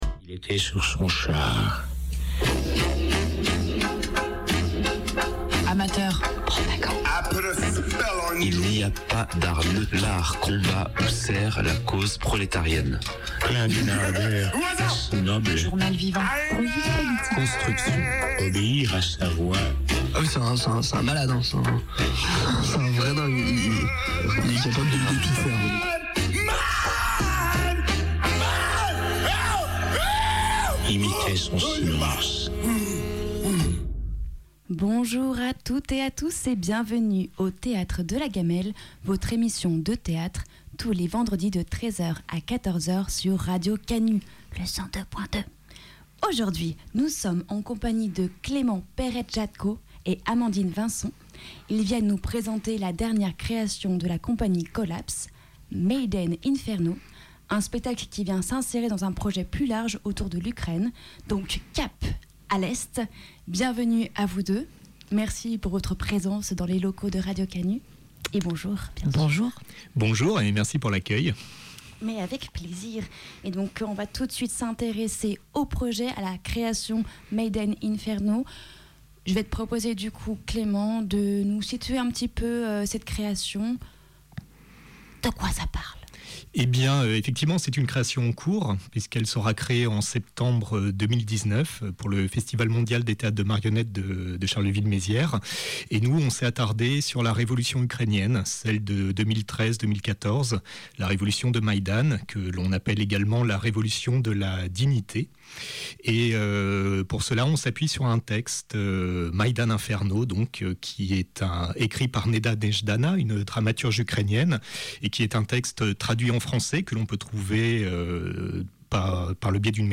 Aujourd’hui nous recevons la Compagnie Collapse, compagnie de théâtre d’ombre et de marionnettes, venue nous parler de son projet sur la révolution Ukrainienne de 2014, Maïdan Inferno de Neda Nejdana.